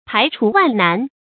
排除万难 pái chú wàn nán
排除万难发音
成语正音难，不能读作“nàn”。